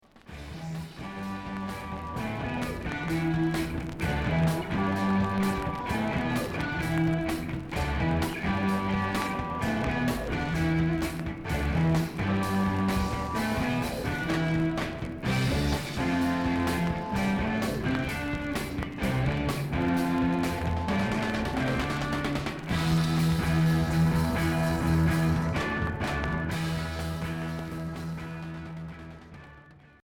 Heavy rock Unique 45t retour à l'accueil